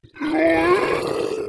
zombie_voice_idle12.wav